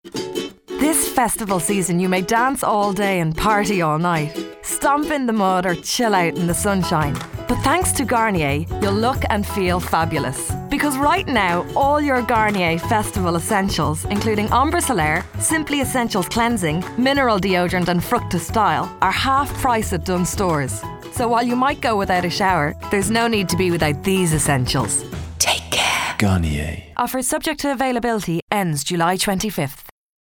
Husky tones, gravelly voice, deep timber, mature, baritone, sexy
Sprechprobe: Werbung (Muttersprache):